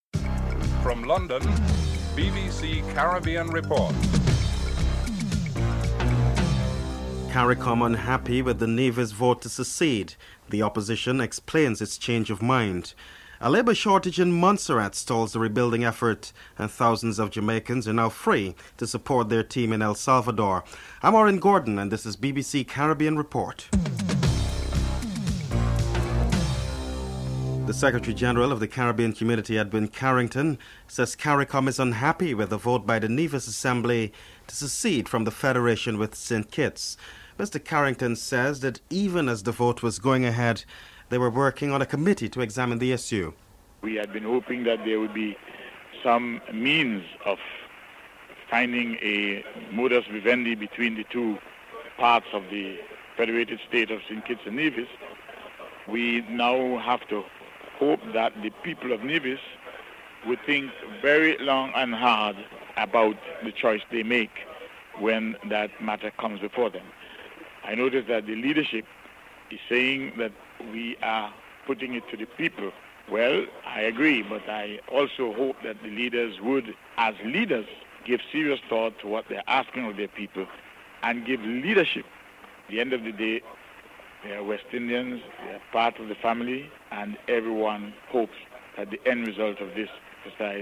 2. Caricom is unhappy with the Nevis vote to secede and the Opposition explains its change of mind. Edwin Carrington, Secretary General of CARICOM and Joseph Parry, Opposition Leader are interviewed (00:27-05:51)